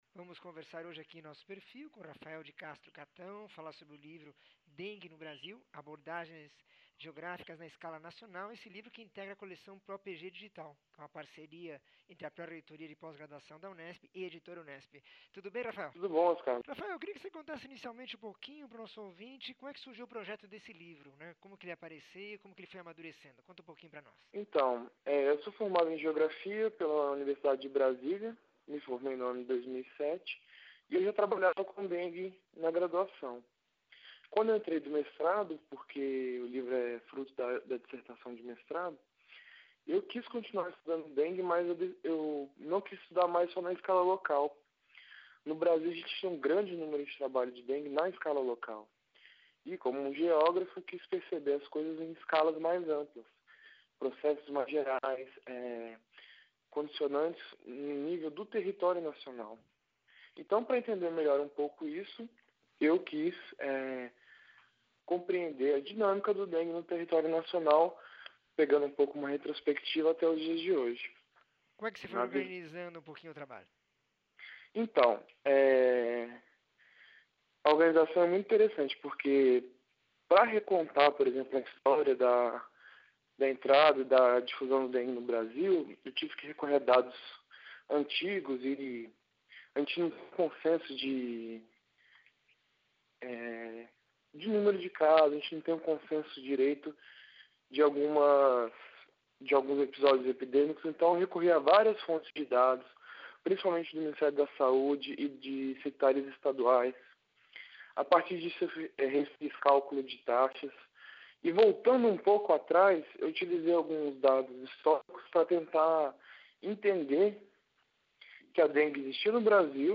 entrevista 1714